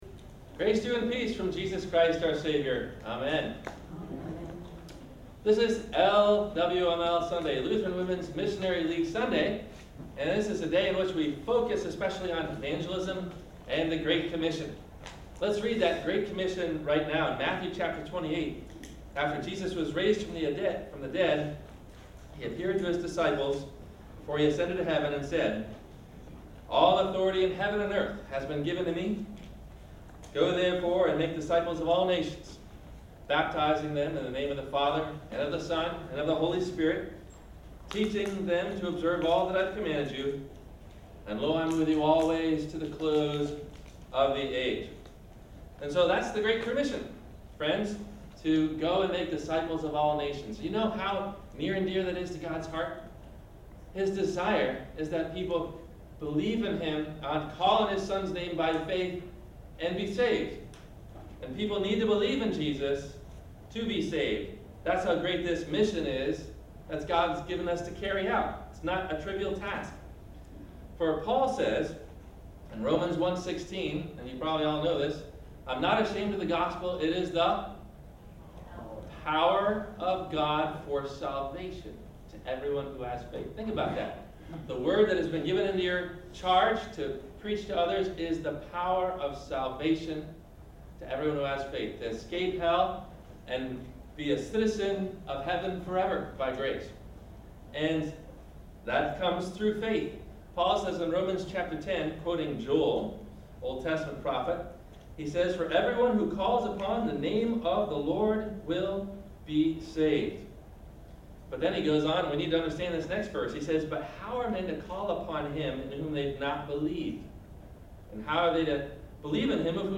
Why Does God Send Us and Not An Angel? – Sermon – October 06 2013